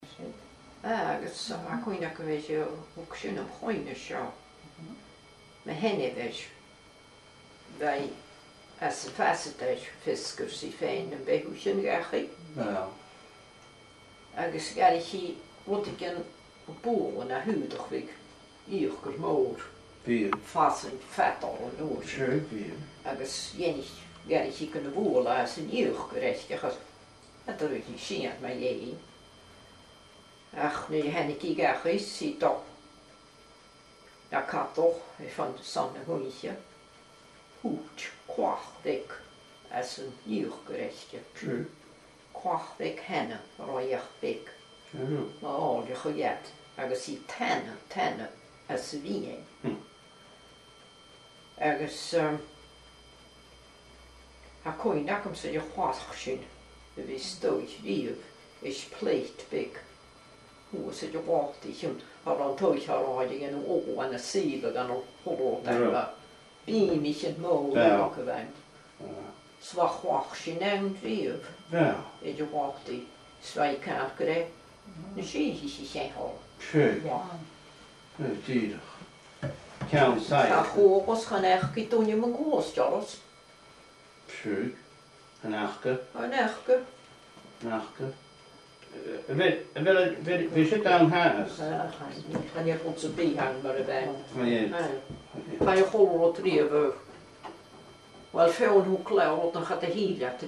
Fear-agallaimh